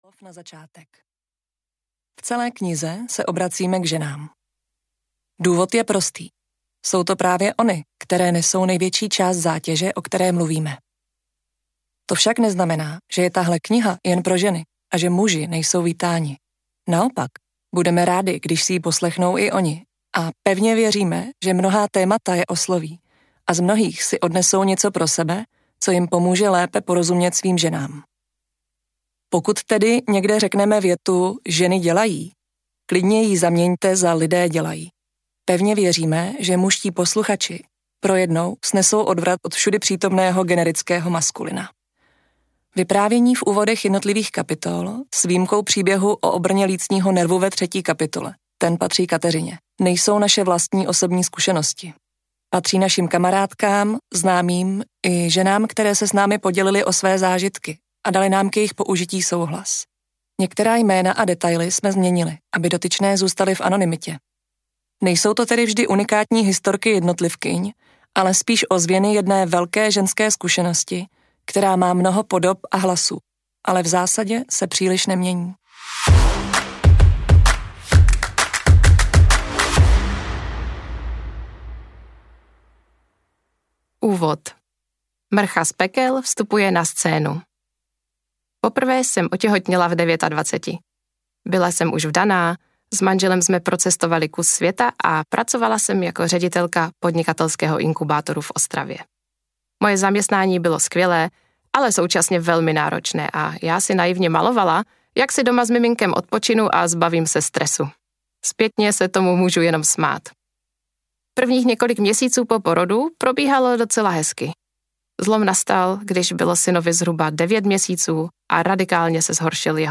Přetíženy audiokniha
Ukázka z knihy